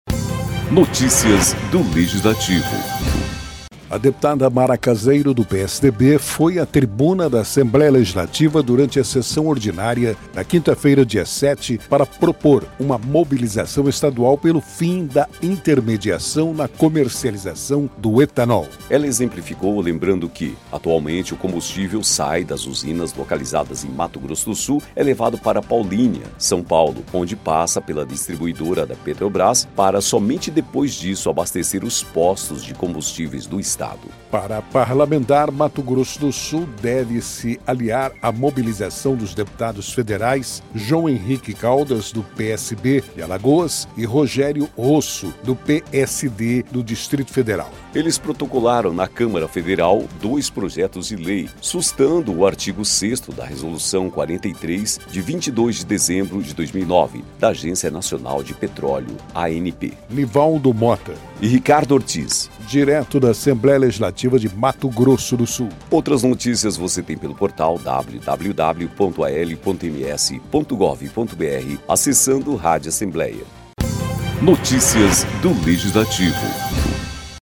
A deputada Mara Caseiro (PSDB) foi à tribuna da Assembleia Legislativa, durante a sessão ordinária desta quinta-feira (7), para propor uma mobilização estadual pelo fim da intermediação na comercialização do etanol.